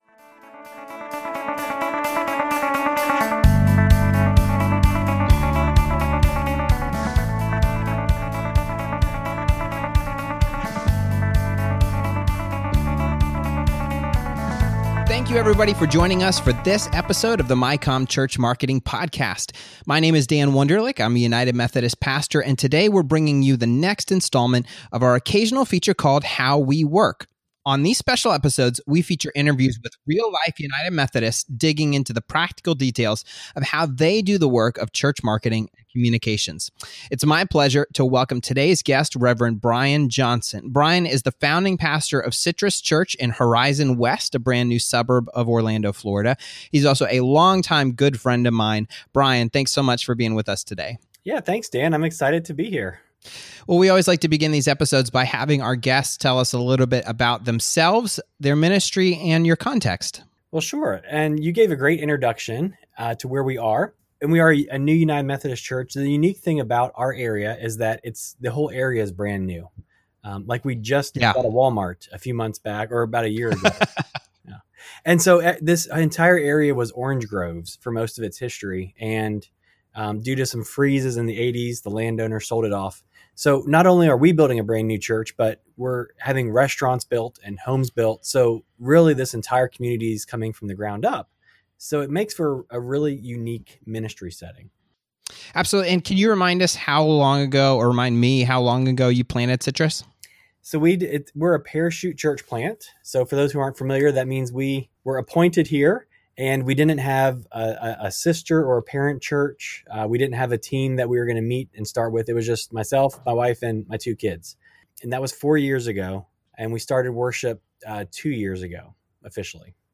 On these special episodes, we feature interviews with real-life United Methodists digging into the practical details of how they do the work of church marketing and communications.